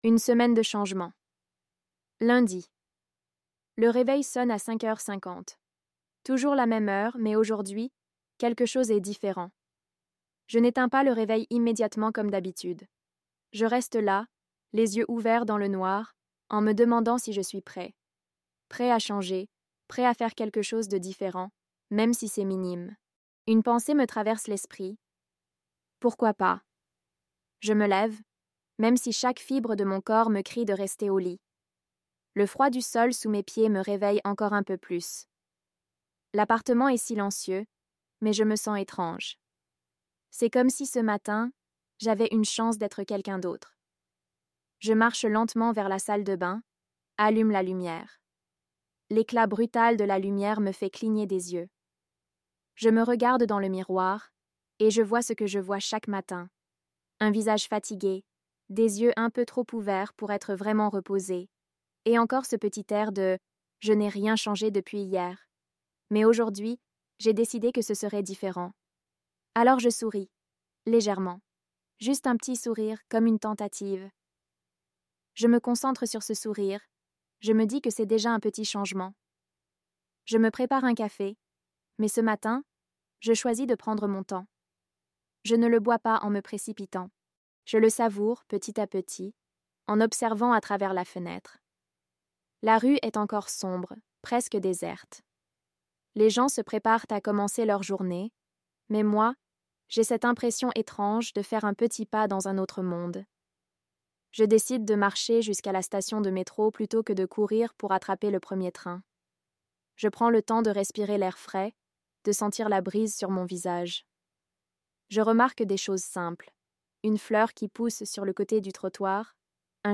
Apprenez le français avec un dialogue pratique + PDF.